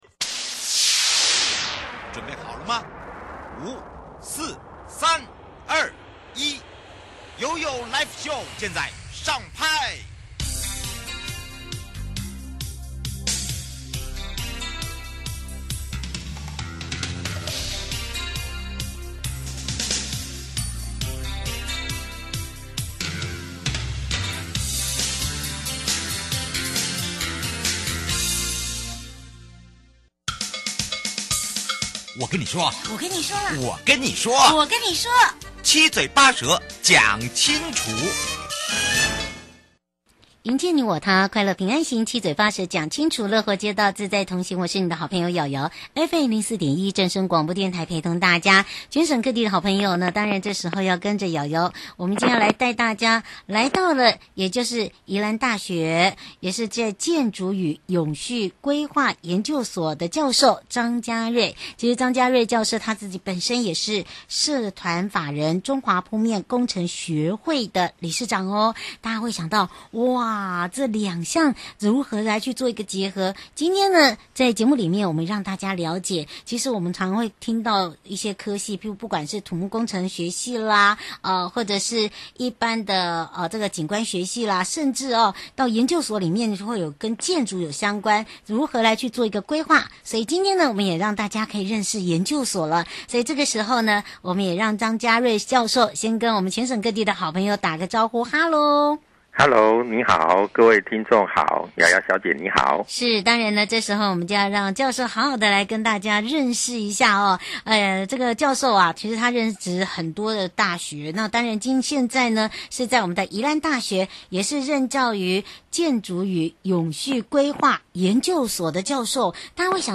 受訪者： 營建你我他-快樂平安行-七嘴八舌講清楚- 政府推動中的(前瞻基礎建設計畫)如何提升道路服務品質?城鄉